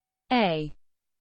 alphabet char sfx